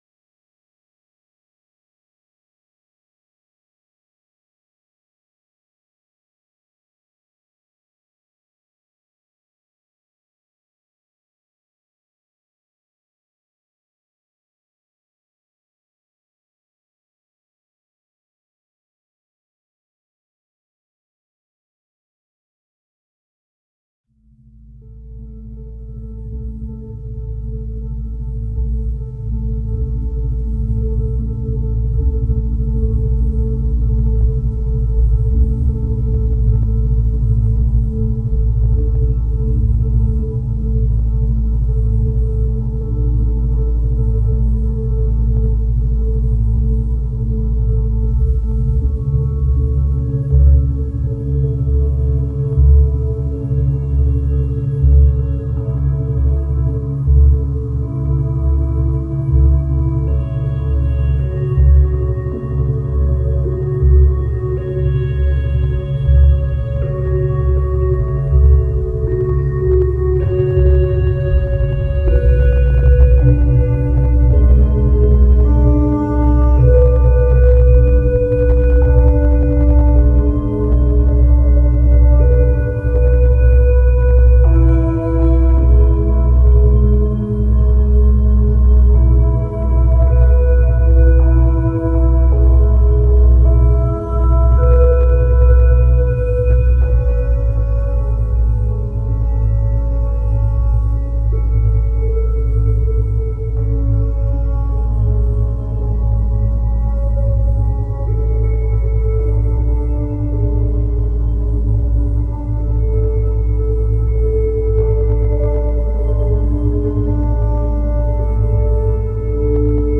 Der QUICK-SLEEPER beinhaltet eine Zusammenstellung von Isochronen Tönen und Binauralen Beats.